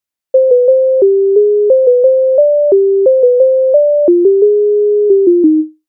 MIDI файл завантажено в тональності c-moll